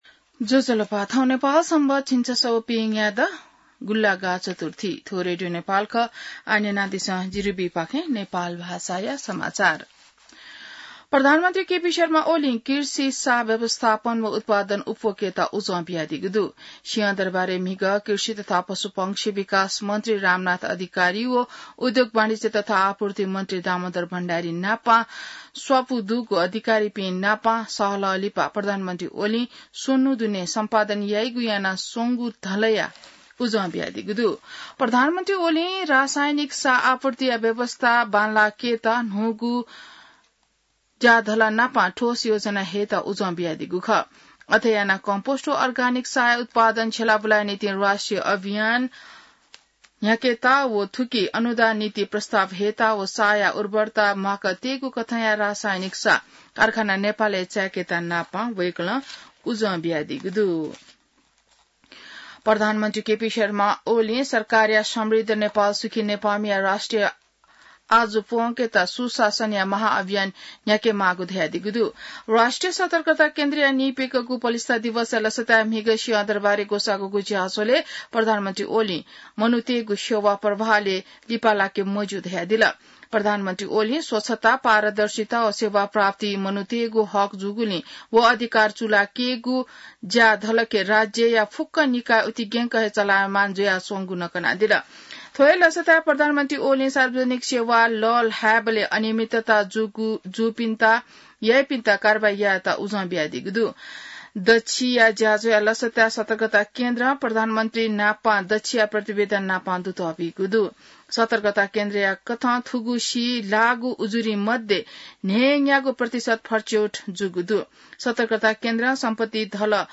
नेपाल भाषामा समाचार : २८ साउन , २०८२